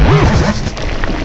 cry_not_tangrowth.aif